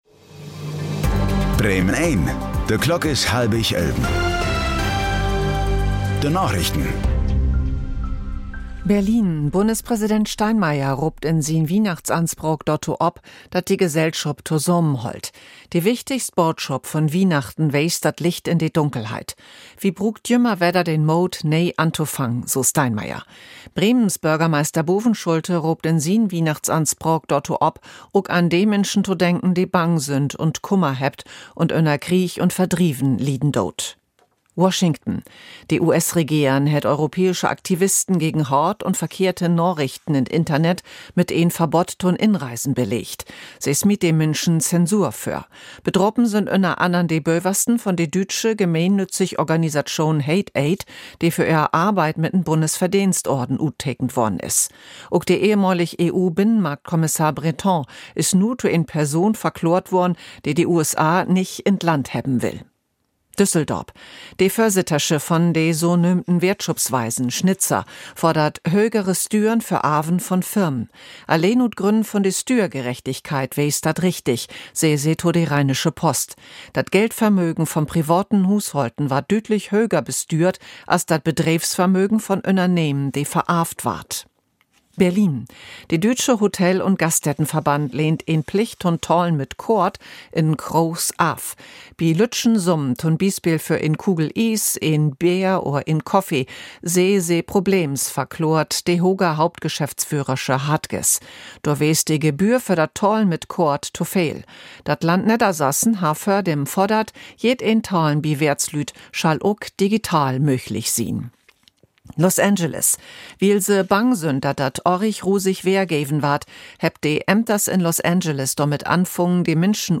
Plattdüütsche Narichten vun'n 24. Dezember 2025